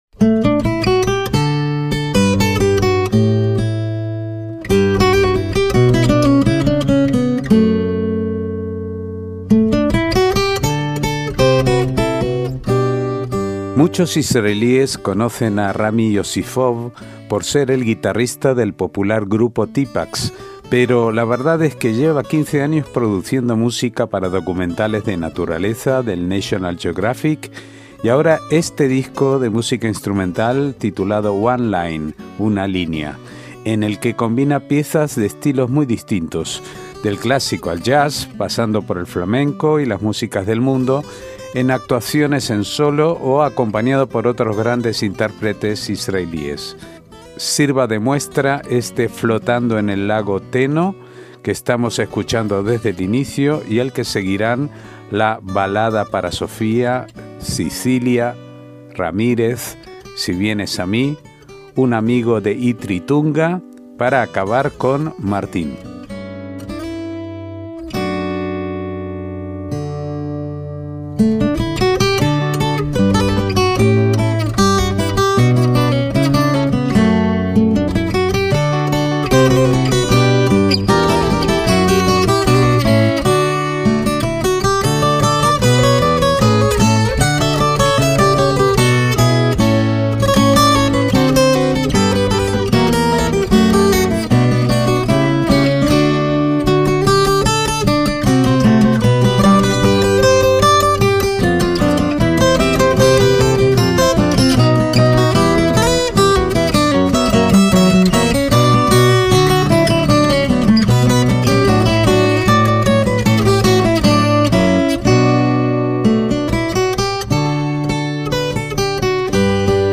MÚSICA ISRAELÍ
disco de música instrumental